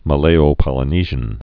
(mə-lāō-pŏlə-nēzhən)